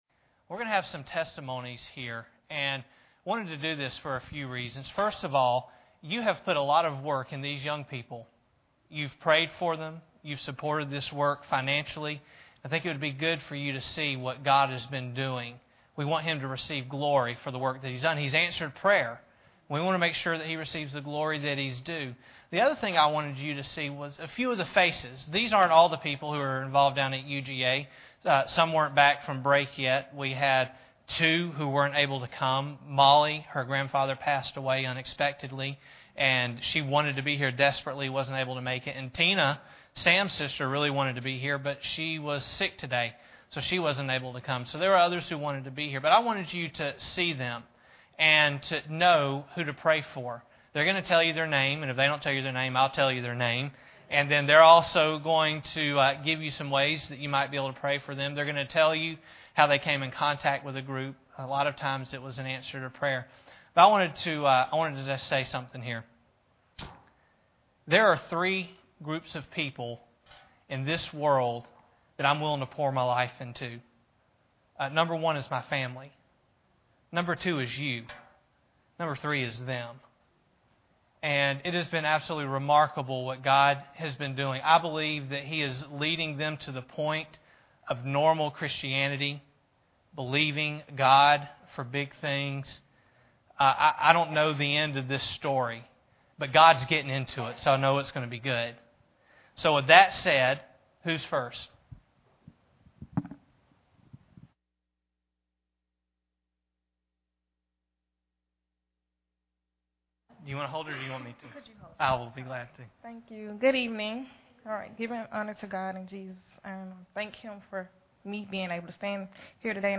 Several students gave testimonies of how the Life Purpose Bible Fellowship has touched their lives.
Service Type: Sunday Evening